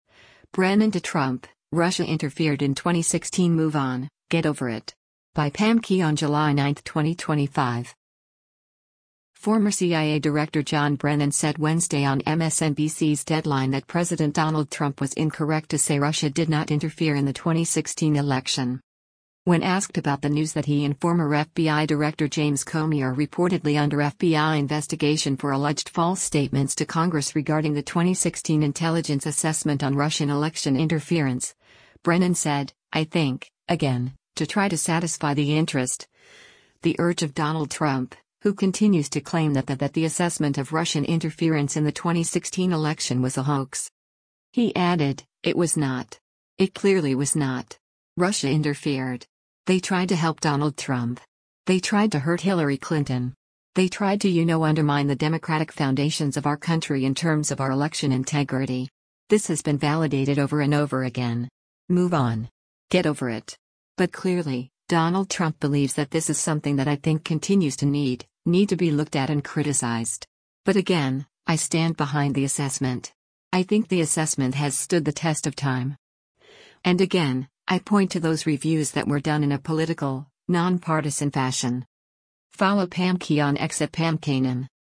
Former CIA Director John Brennan said Wednesday on MSNBC’s “Deadline” that President Donald Trump was incorrect to say Russia did not interfere in the 2016 election.